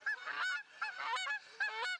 stim172_geese.wav